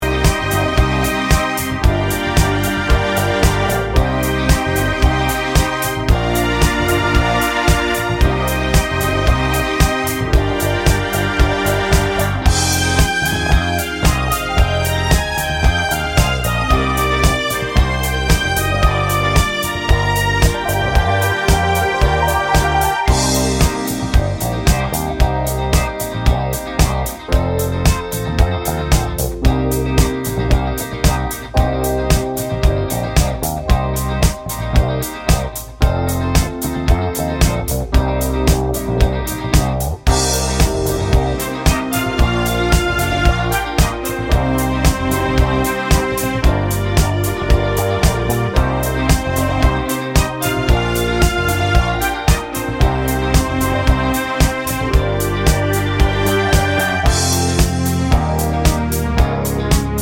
Live Version Pop (1980s) 4:28 Buy £1.50